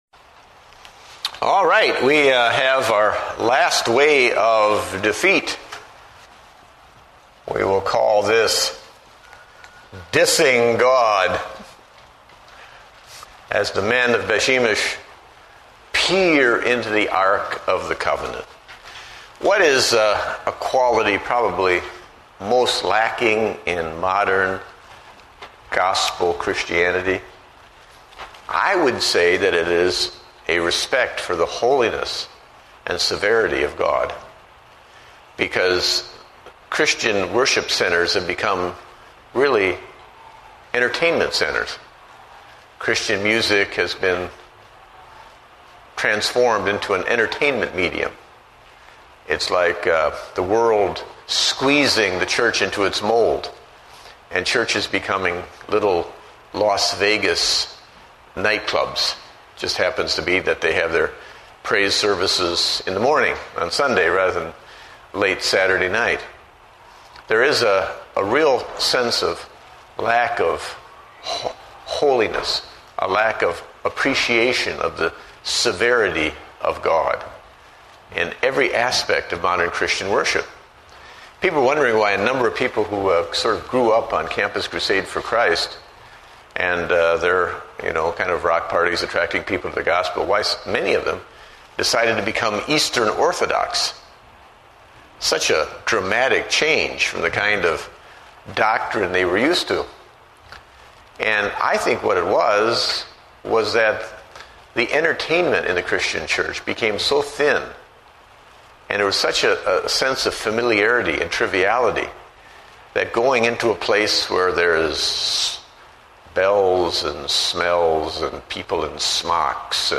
Date: August 17, 2008 (Adult Sunday School)